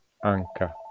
IPA[ˈaŋka]